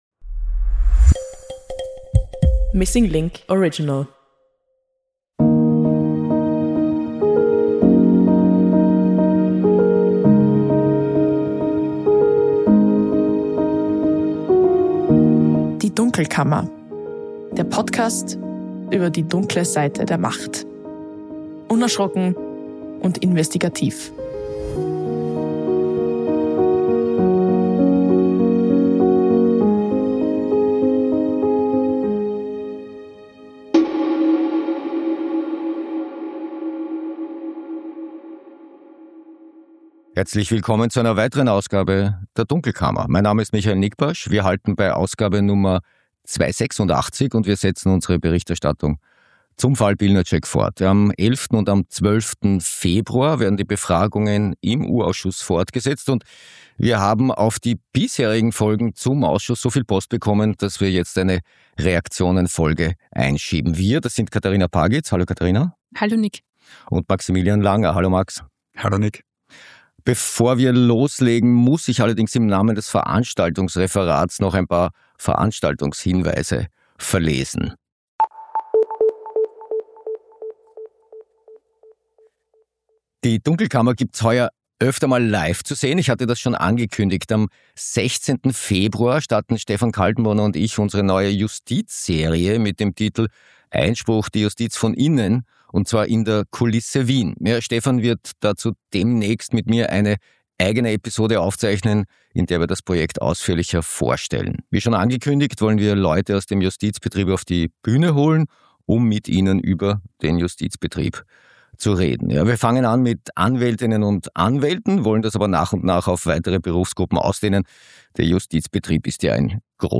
Wir haben auf die Berichterstattung zu den ersten vier Ausschusstagen einige Fragen und Kommentare erhalten, auf die wir in dieser Episode eingehen. Im Studio